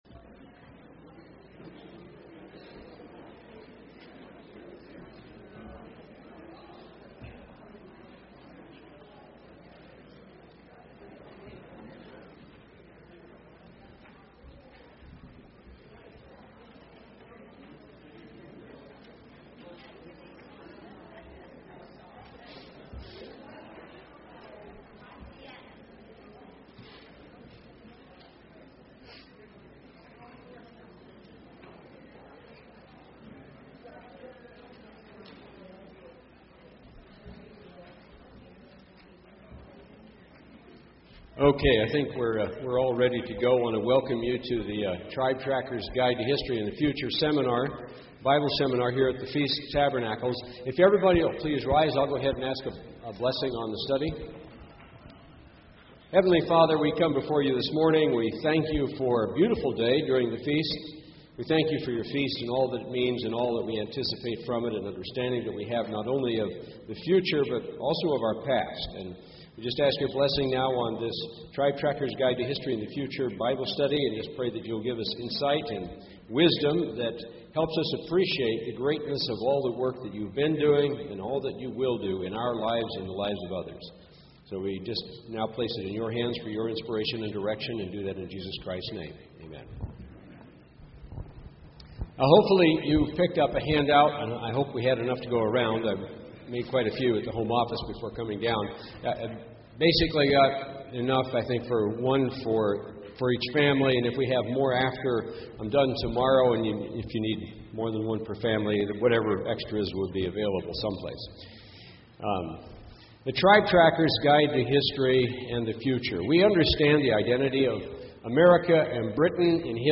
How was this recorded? This sermon was given at the Gatlinburg, Tennessee 2013 Feast site.